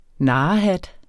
Udtale [ ˈnɑːɑ- ]